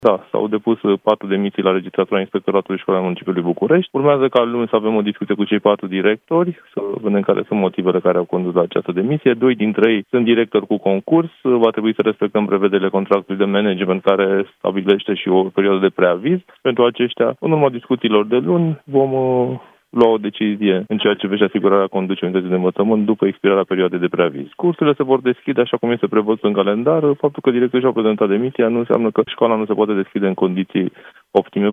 Șeful Inspectoratului Școlar al Municipiului București, Florian Lixandru a spus la Europa FM că demisia celor 4 directori nu va afecta desfășurarea orelor, iar părinții nu trebuie să fie îngrijorați.